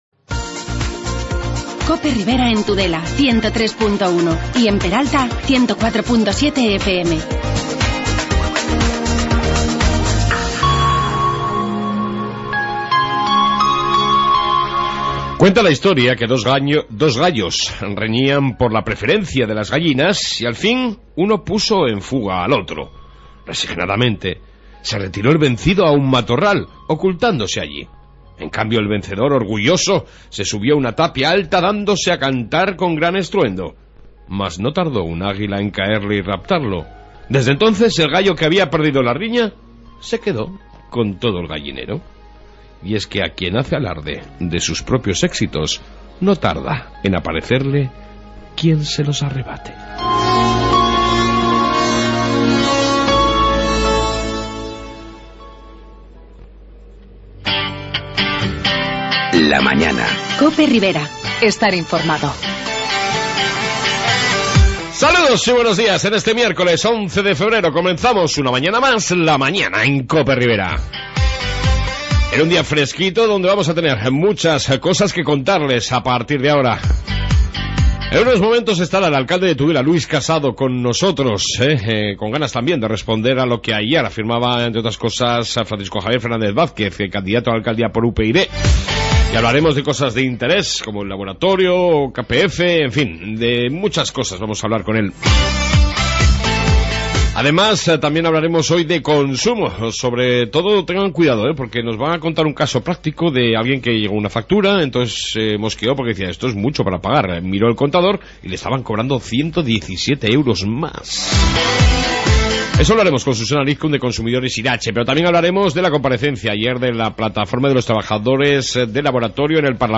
AUDIO: Amplia entrevista con el Alcalde de Tudela Luis Casado...